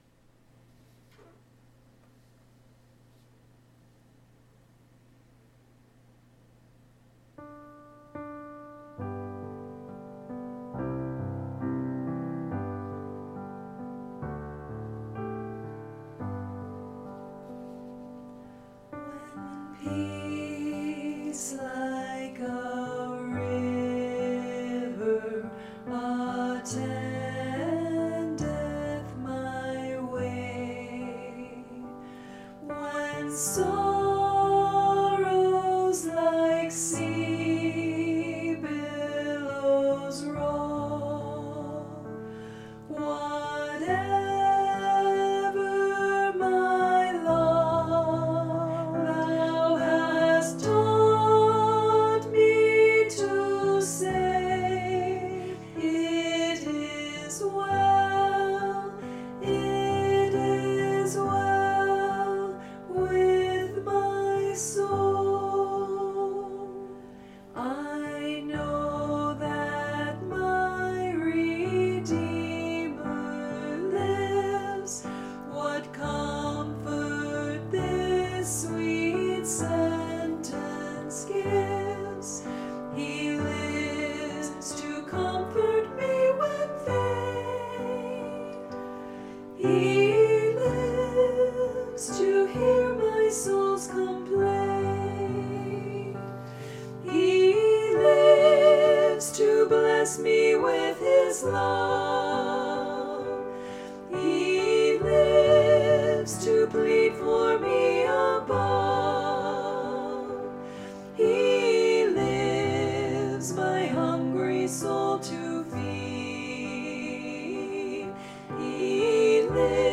Voicing/Instrumentation: SA , SAA , Duet , Trio We also have other 75 arrangements of " I Know That My Redeemer Lives ".